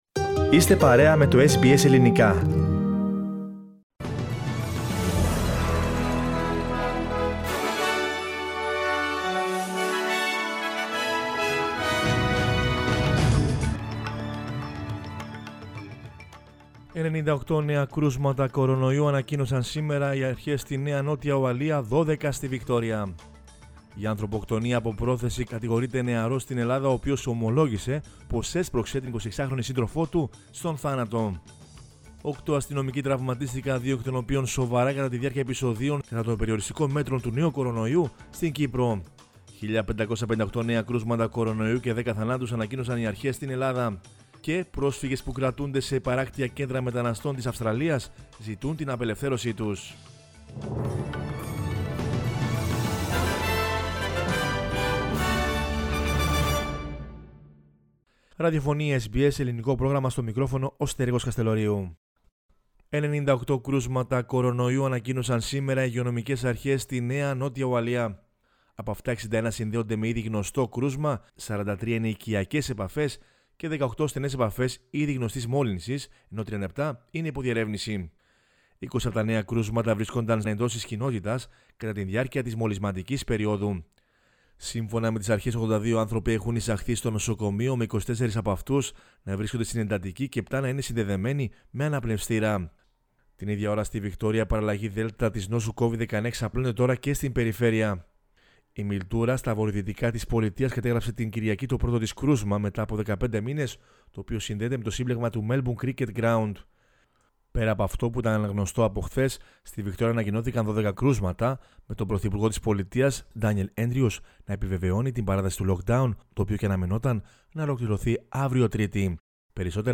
News in Greek from Australia, Greece, Cyprus and the world is the news bulletin of Monday 19 July 2021.